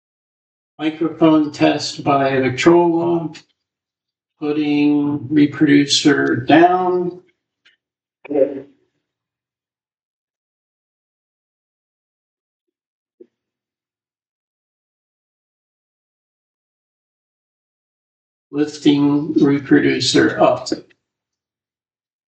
Victrola Test (Internal Mic Records My Voice But Not The Loud 78 Record)
0-7 sec (me talking by the Victrola, about six feet from the laptop)
8 sec (a brief distorted sound when I set the reproducer/needle on the record)
The recording of my voice using the cheap, internal microphone at that distance was surprisingly good. Interestingly, during the 9-17 second interval while the music is playing, significantly louder than my voice, it is completely absent from the audio track!